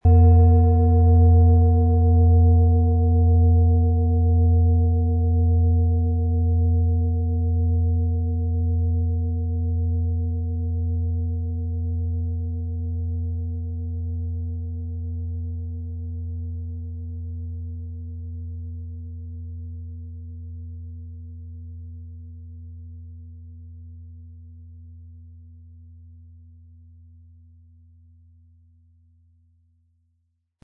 Planetenton 1 Planetenton 2
Planetenschale® Besänftigt sein & Ausgeglichen fühlen mit Chiron & Mond, Ø 27,6 cm inkl. Klöppel
• Mittlerer Ton: Mond
Wie klingt diese tibetische Klangschale mit dem Planetenton Chiron?
Spielen Sie die Chiron mit dem beigelegten Klöppel sanft an, sie wird es Ihnen mit wohltuenden Klängen danken.
MaterialBronze